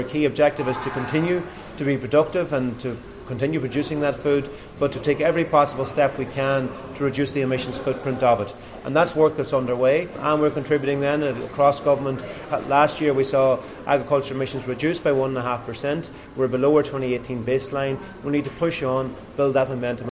Agriculture Minister Charlie McConologue says they are making strides in the area………….